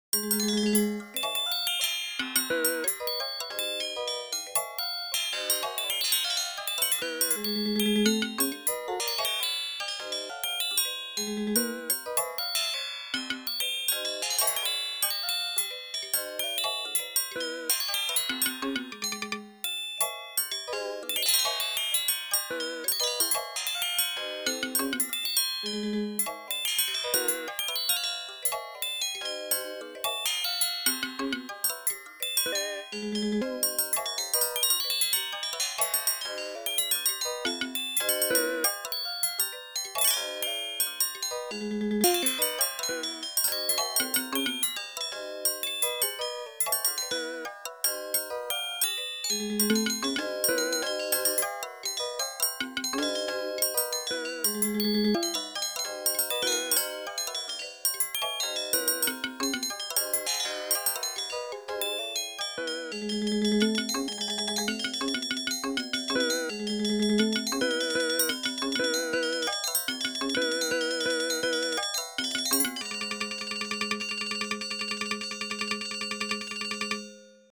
Four machine performances
These four recordings were all created using the Assistant Performer alone, without a live performer.
4. speed twice as fast as notated in the score, minimum ornament chord duration set to 100 milliseconds.